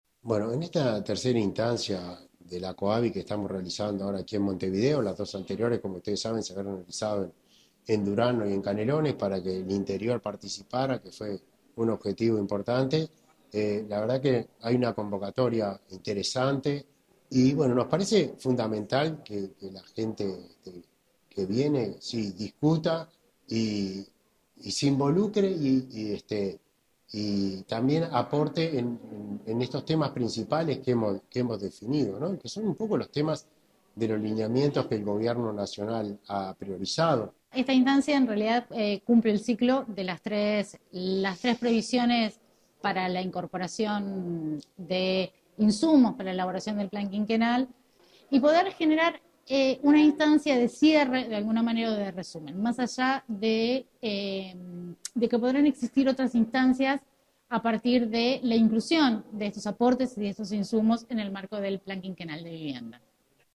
Palabras de los directores del MVOT Milton Machado y Paola Florio
Palabras de los directores del MVOT Milton Machado y Paola Florio 18/06/2025 Compartir Facebook X Copiar enlace WhatsApp LinkedIn En ocasión del tercer encuentro de la Comisiones Asesoras de Vivienda y Ordenamiento Territorial, se expresaron el director nacional de Vivienda, Milton Machado, y la directora de Ordenamiento Territorial, Paola Florio.